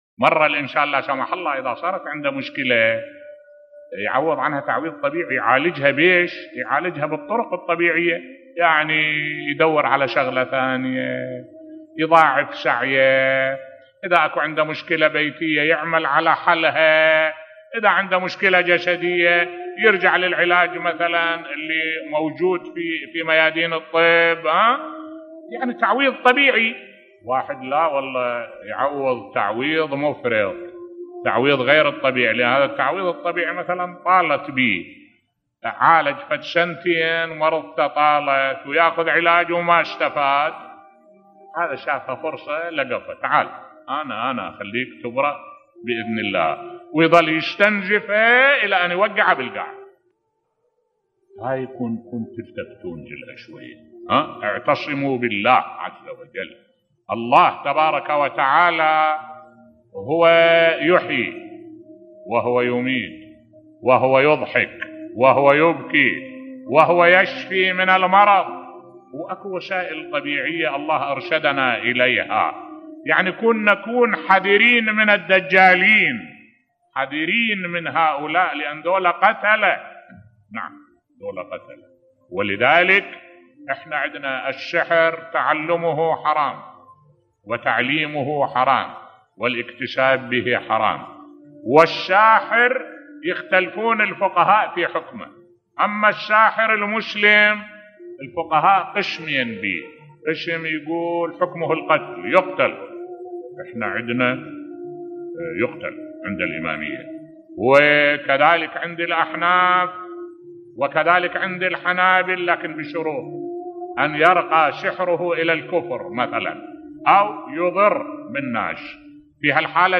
ملف صوتی ما هي الطرق الطبيعية و الصحيحة لحل المشاكل بصوت الشيخ الدكتور أحمد الوائلي